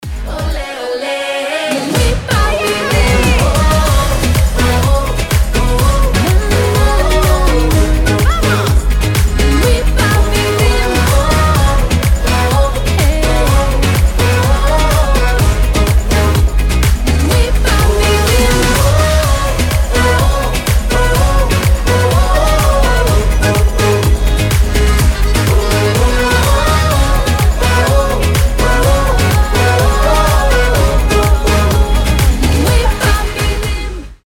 • Качество: 320, Stereo
поп
позитивные
зажигательные
воодушевляющие